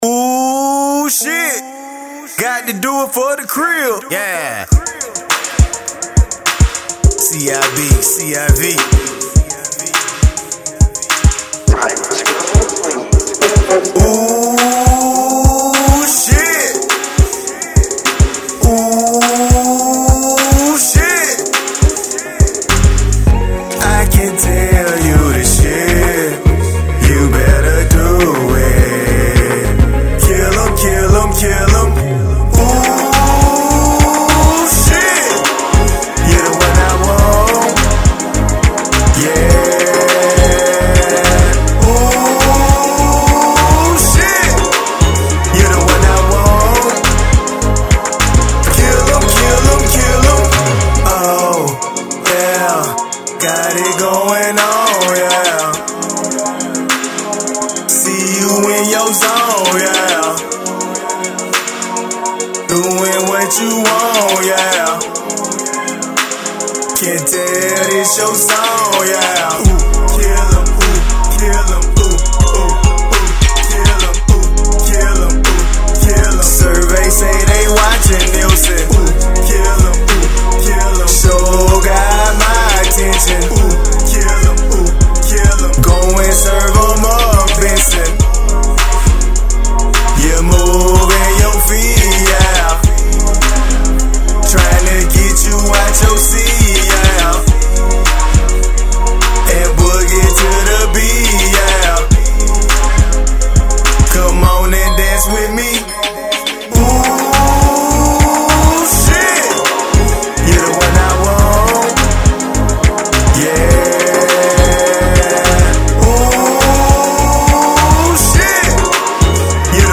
Rap
New Skool mixed with some Old Skool with a party vice